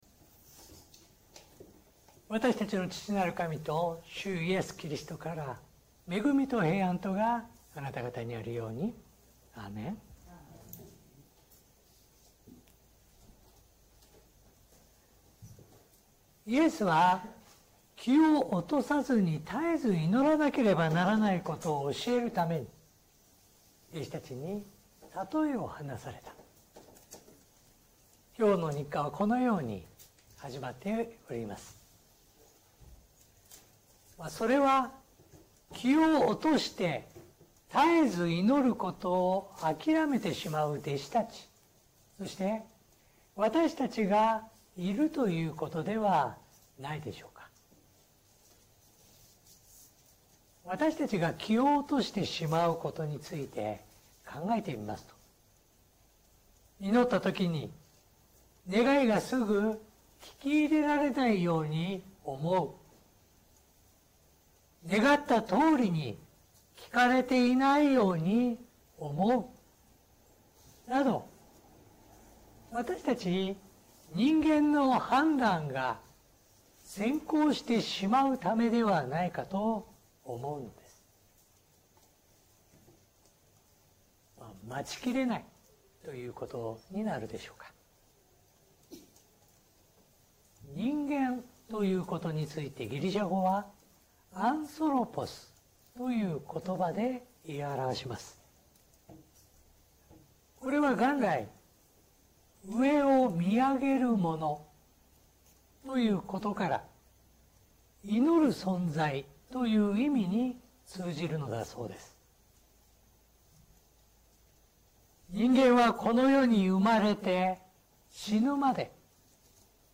説教音声 最近の投稿 2026年3月22日 礼拝・四旬節第5主日 3月22日 「死んでも生きる？」